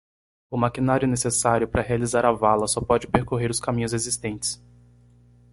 Read more to travel to roam, wander Frequency C1 Pronounced as (IPA) /peʁ.koˈʁe(ʁ)/ Etymology Inherited from Latin percurrō In summary From Latin percurrere, equivalent to per- + correr.